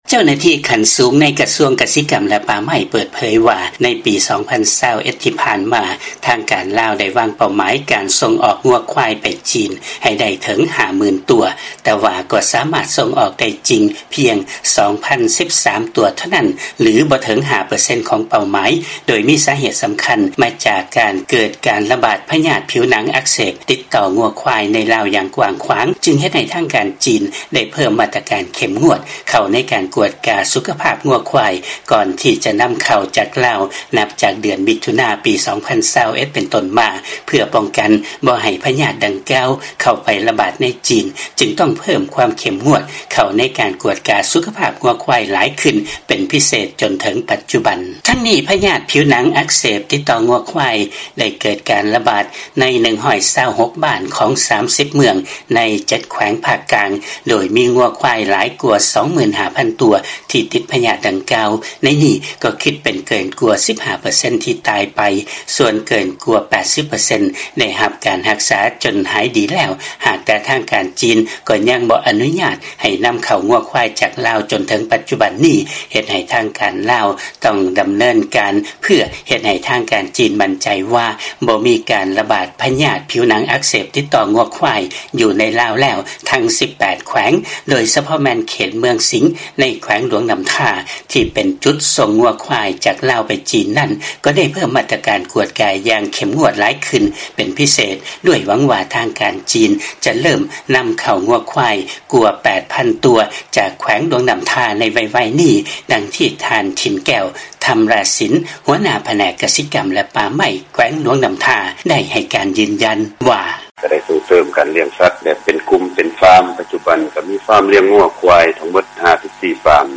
ມີລາຍງານກ່ຽວກັບເລື້ອງນີ້ ຈາກບາງກອກ.